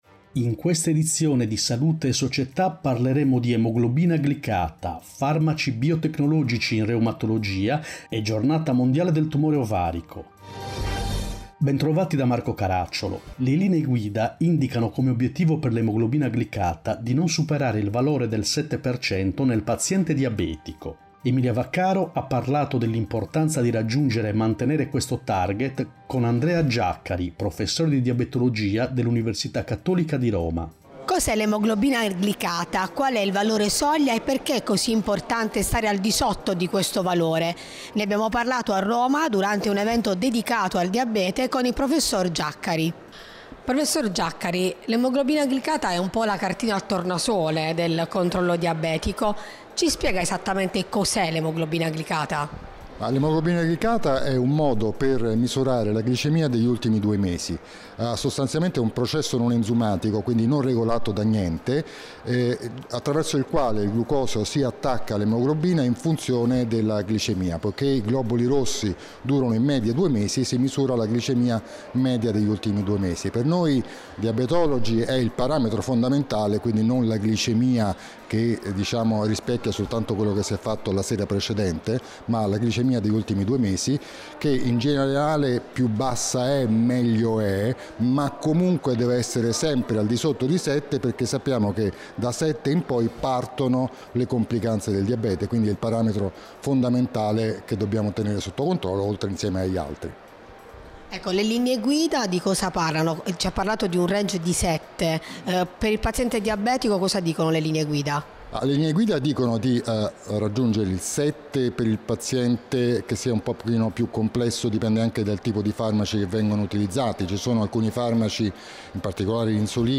In questa edizione: 1. Diabete, Che cosa è l’emoglobina glicata 2. Reumatologia, I farmaci biotecnologici 3. Oncologia, Giornata Mondiale del Tumore Ovarico Interviste